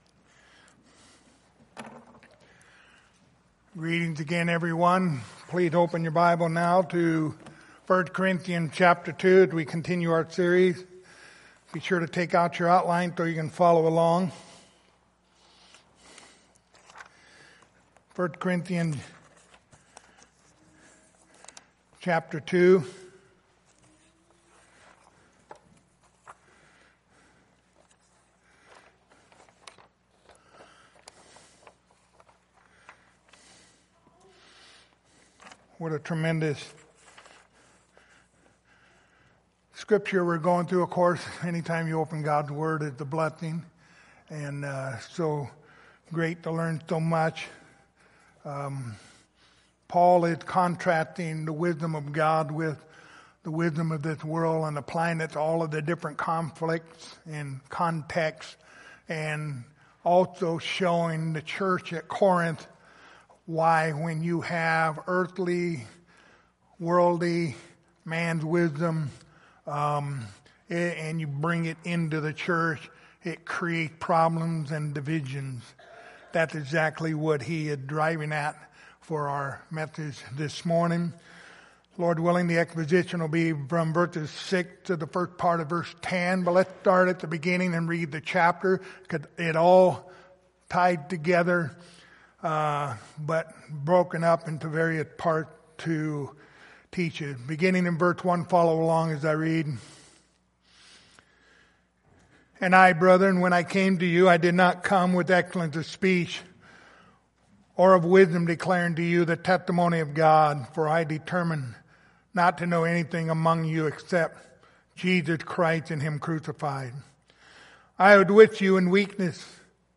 1 Corinthians Service Type: Sunday Morning Topics